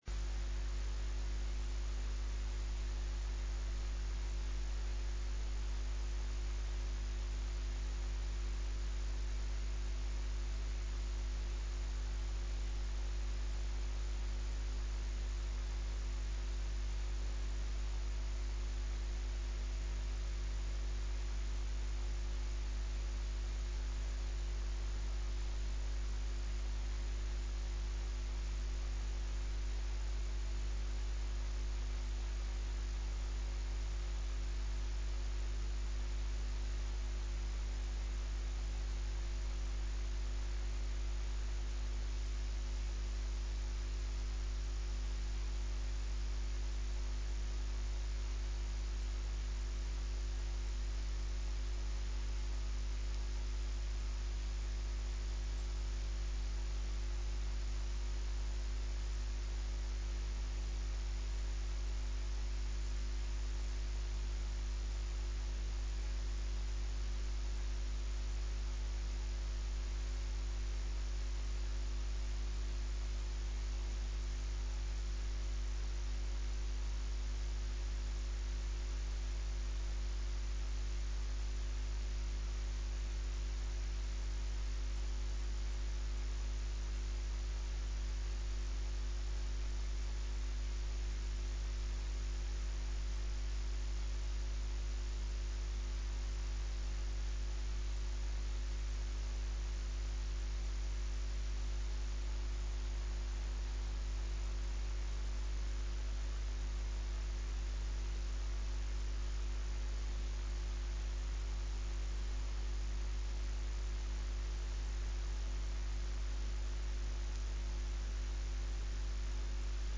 29 Bayan E Jummah 19 July 2024 (12 Muharram 1446 HJ)